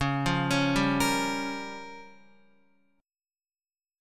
Db6b5 chord